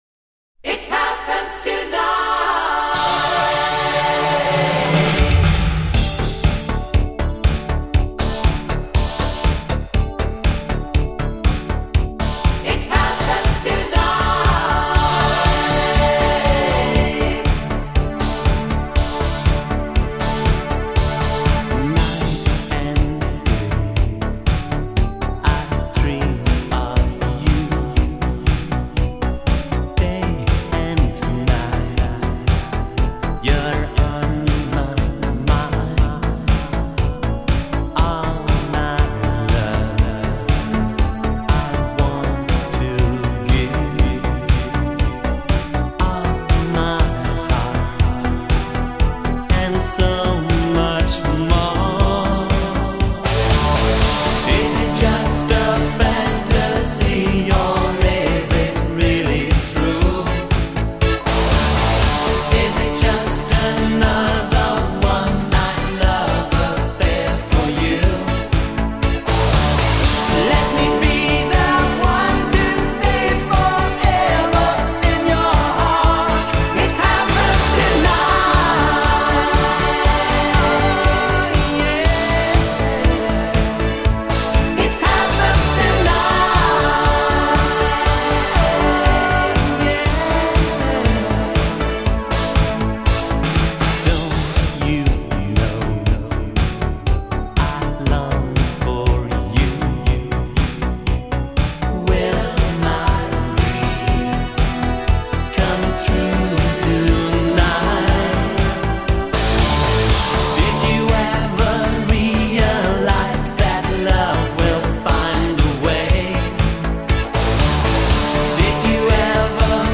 音乐类型：Synth-pop, Eurodisco
经典的荷东舞曲，全新制作，是荷东迷难得的一张大碟。